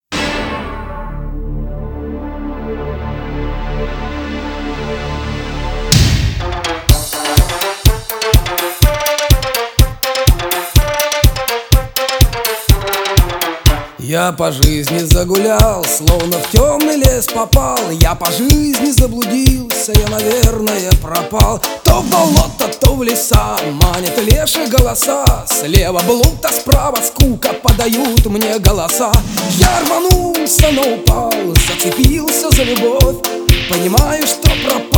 Жанр: Поп музыка / Русский поп / Русские